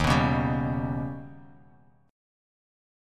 Eb+7 Chord
Listen to Eb+7 strummed